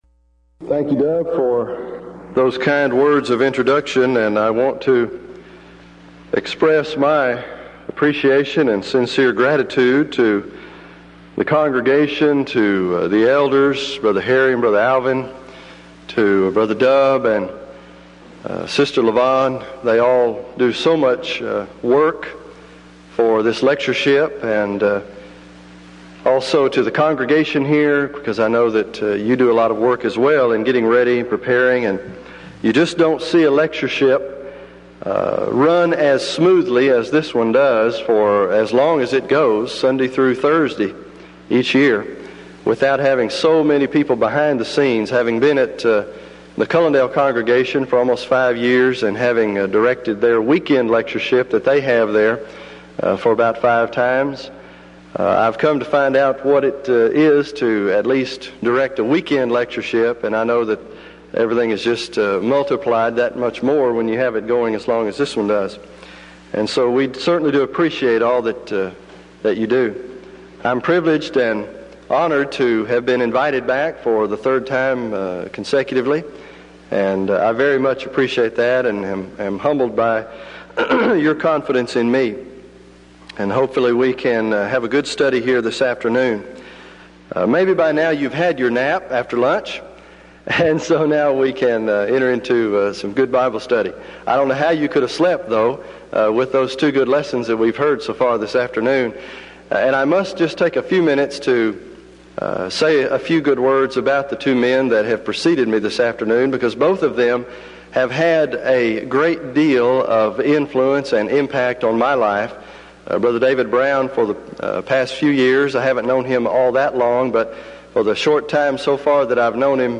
Event: 1992 Denton Lectures Theme/Title: Studies In Ezra, Nehemiah And Esther
lecture